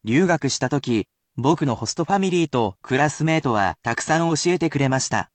Though I will read these sentences personally at a normal speed for more advanced learners, it will still help you learn how to use it.